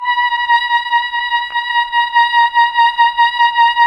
Index of /90_sSampleCDs/Roland LCDP09 Keys of the 60s and 70s 1/KEY_Chamberlin/VOX_Chambrln Vox